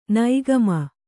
♪ naigama